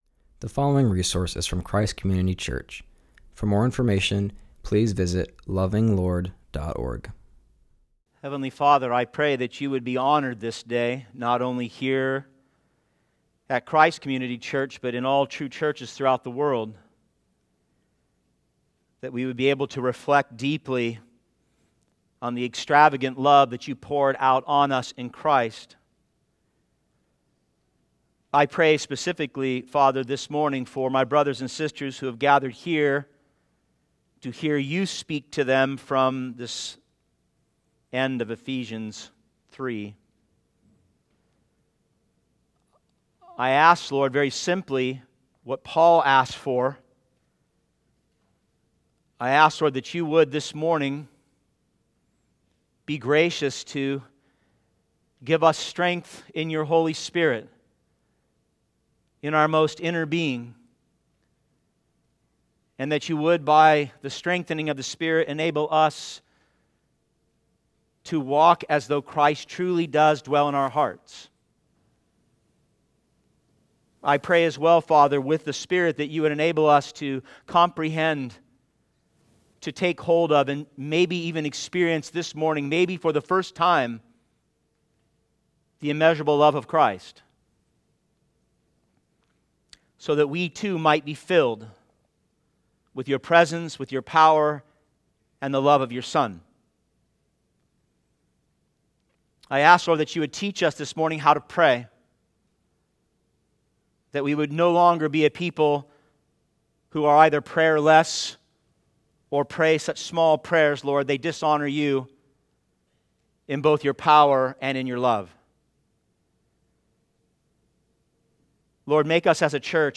continues our series and preaches from Ephesians 3:14-21.